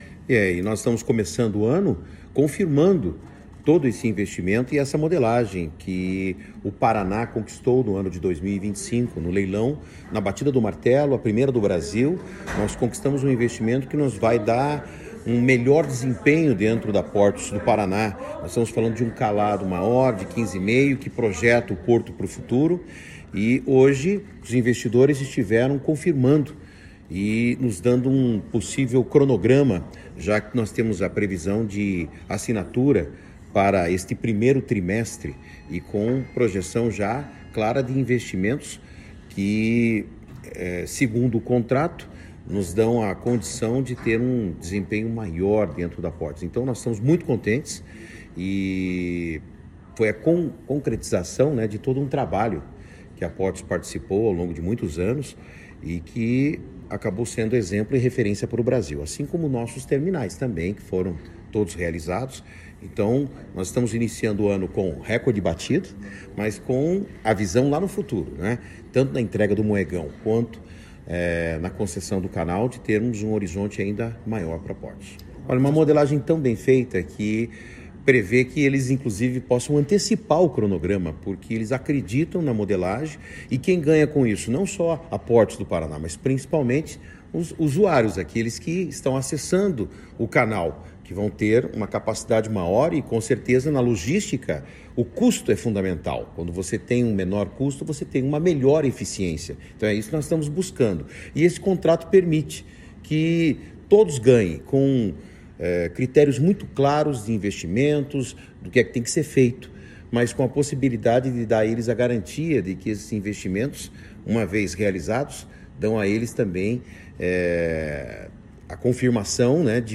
Sonora do secretário de Infraestrutura e Logística, Sandro Alex, sobre a concessão do Canal da Galheta